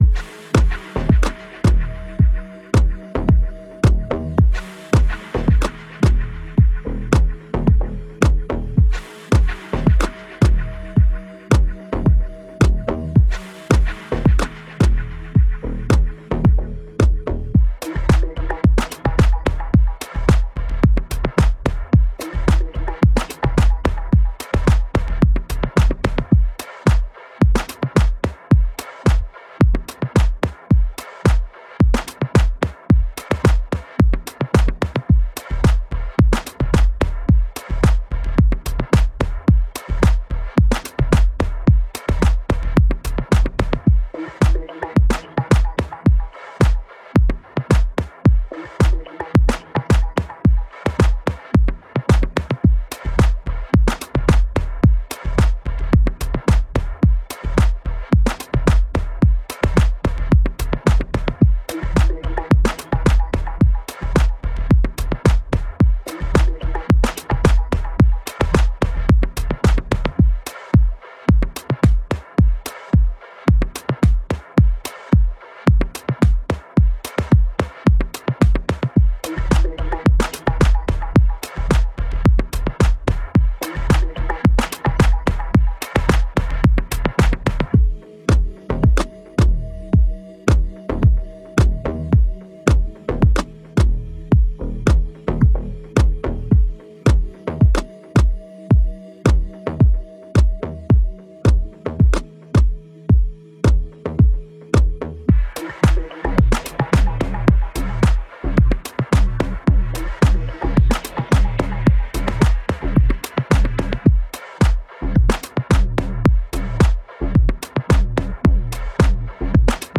House Music, Deep House, Bass Boosted